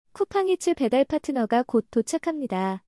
1. 배달파트너 매장 근처 위치 시 목소리 알림
‘쿠팡이츠 배달파트너가 곧 도착해요’ 와 효과음이 같이 들려요.
쿠팡이츠-배달파트너가-곧-도착합니다.mp3